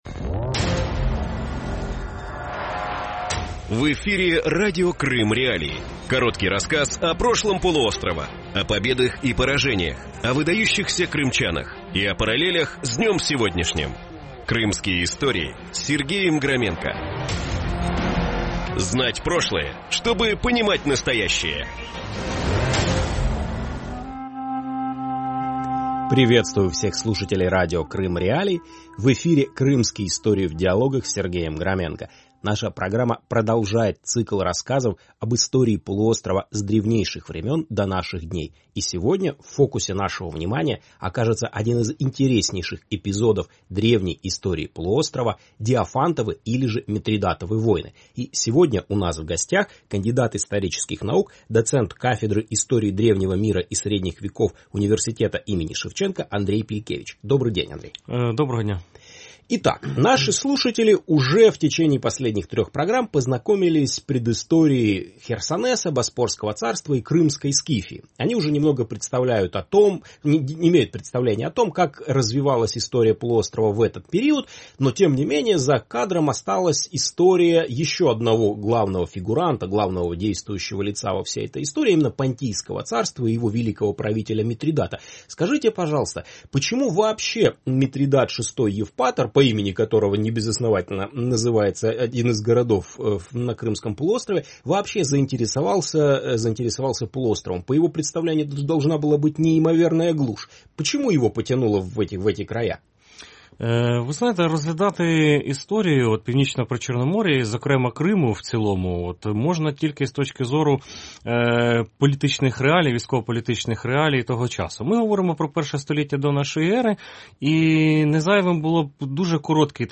Новый цикл Крымских.Историй в диалогах рассказывает об истории Крыма с древнейших времен до наших дней.
Эфир можно слушать Крыму в эфире Радио Крым.Реалии (105.9 FM), а также на сайте Крым.Реалии